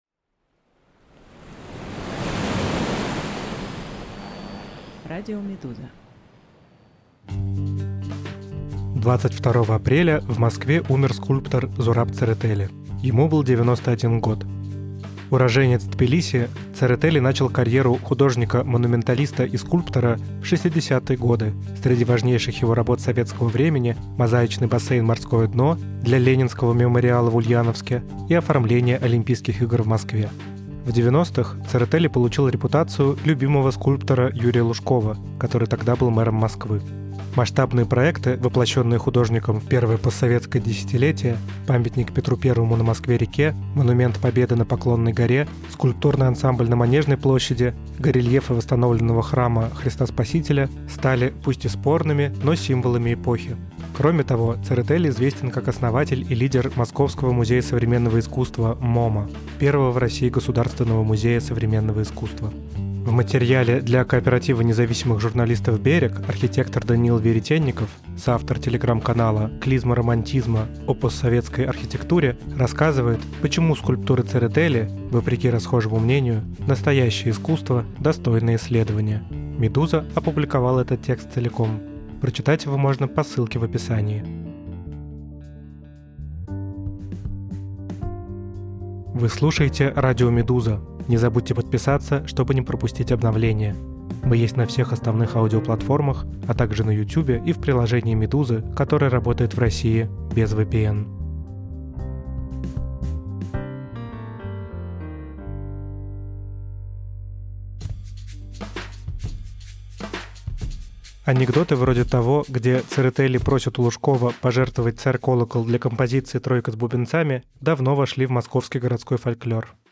Что ждет американскую экономику? Какие страны пострадают от пошлин? И почему России новые тарифы Трампа не коснулись? Аудиоверсия разбора «Медузы».